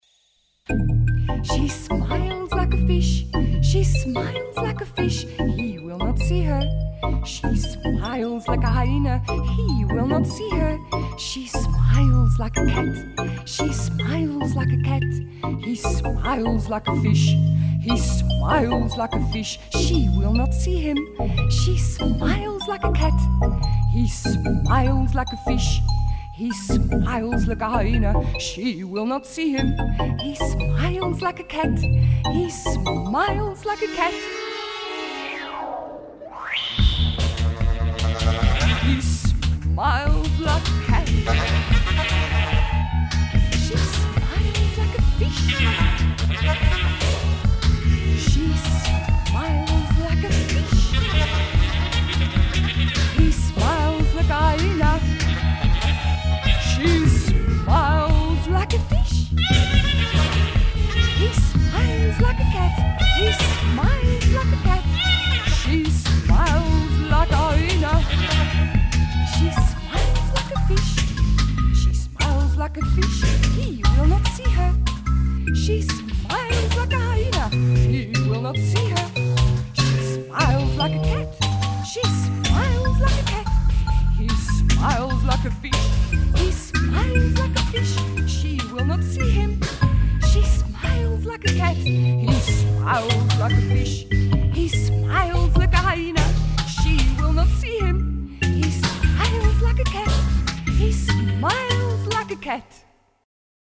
musical improvisations